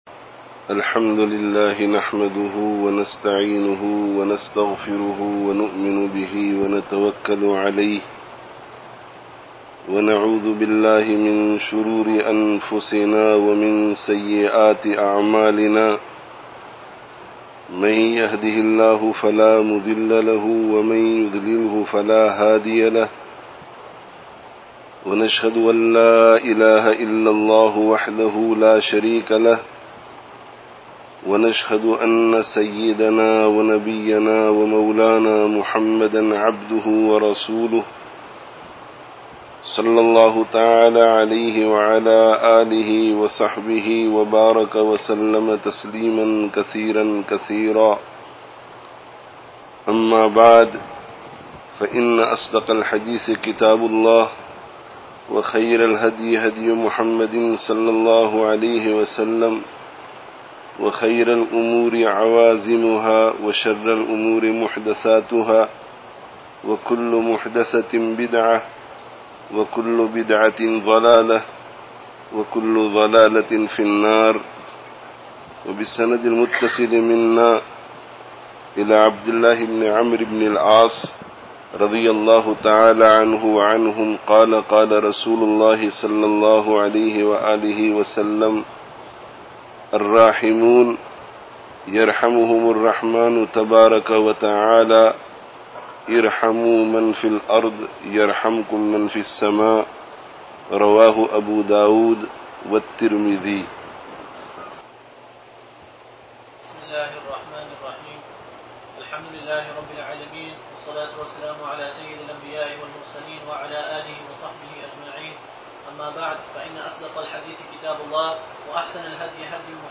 Completion of Sahih-Al-Bukhari (Jamiah Qasimul Uloom, Toronto 14/08/16)